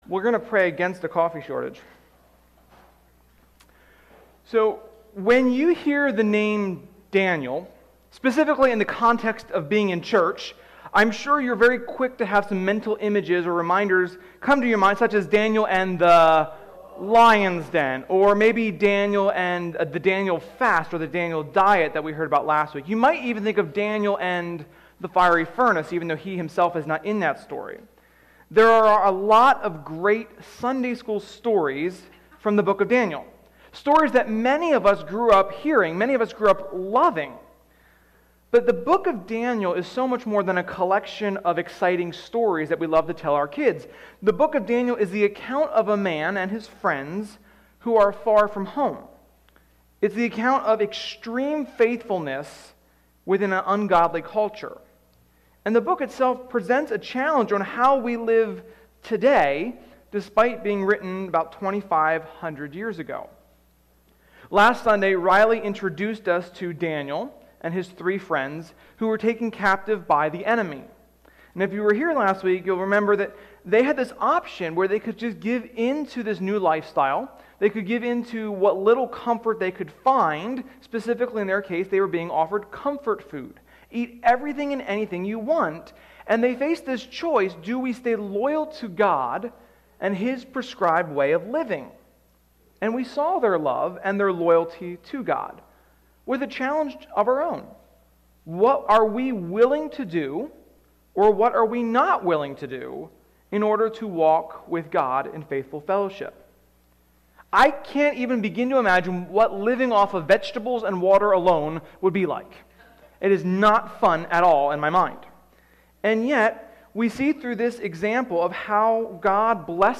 Sermon-6.13.21.mp3